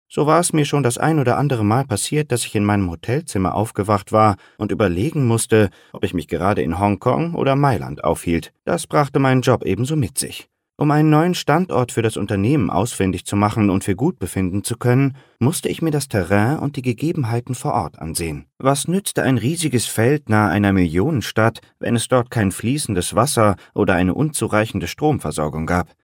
Hörbuch - Der Besondere Zauber von Weihnachten